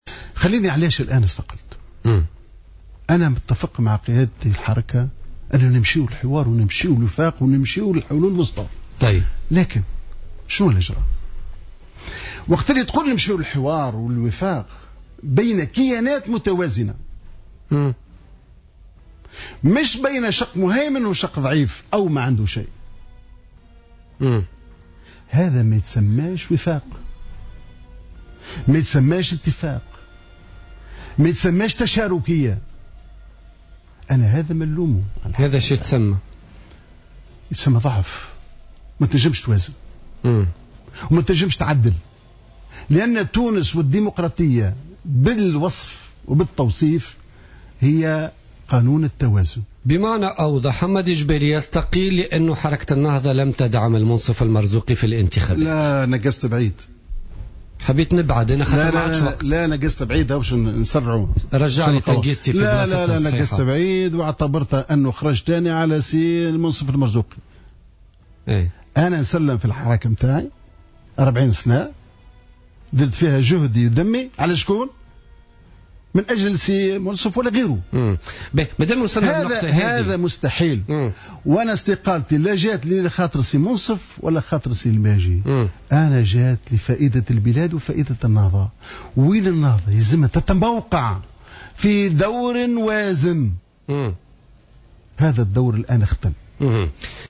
قال القيادي المستقيل من حركة النهضة حمادي الجبالي في تصريح للجوهرة أف أم اليوم...